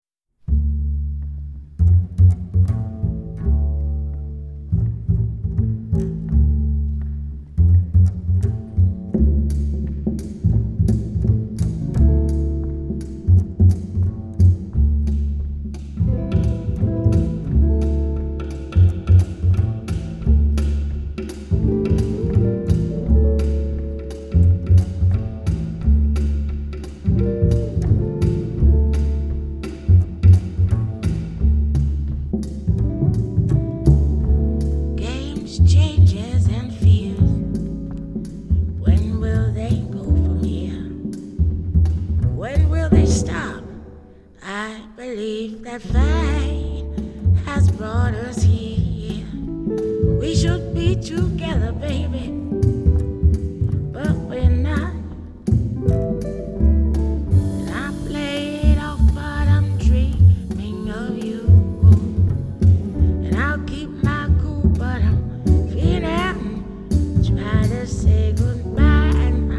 爵士音樂、發燒音樂